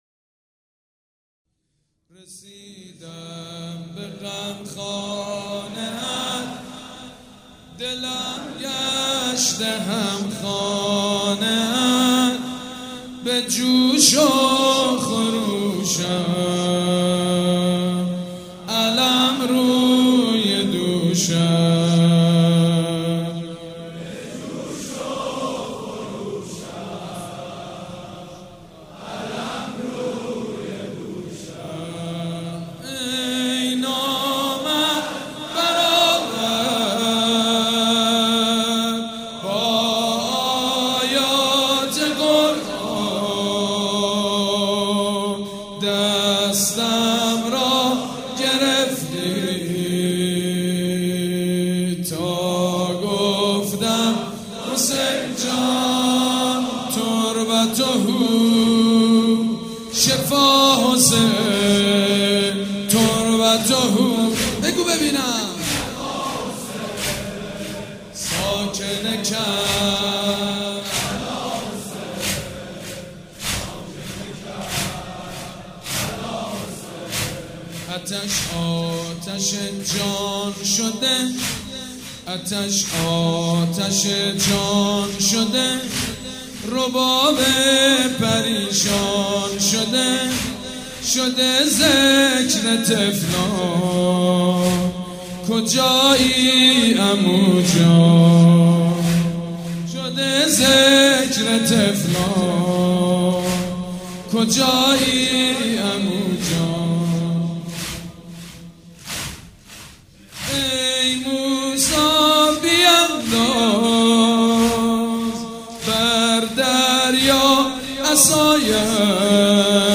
شب نهم محرم الحرام‌
نوحه
مداح
حاج سید مجید بنی فاطمه
مراسم عزاداری شب تاسوعا
nohe-residam be gham khane at.mp3